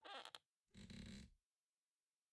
02_孤儿院走廊_跷跷板声音.ogg